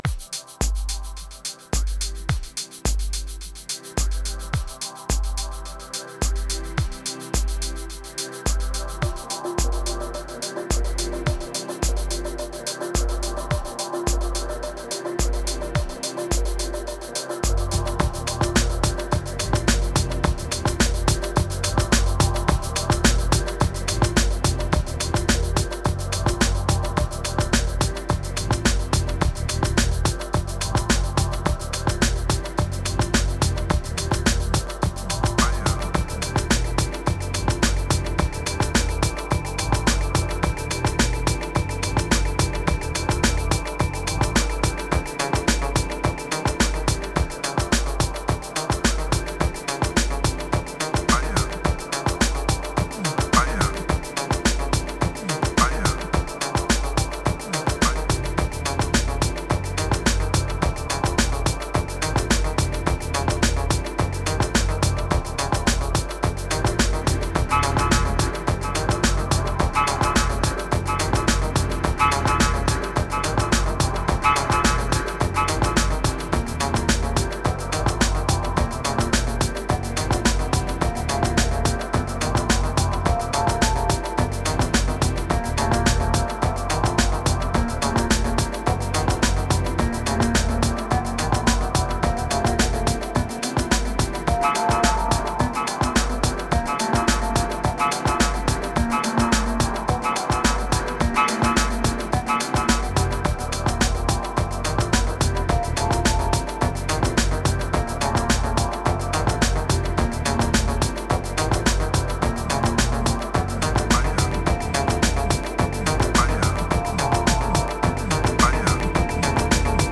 Energy felt so good for a winter day.
Dub Electro Uplifting Orb Energy Kicks Beat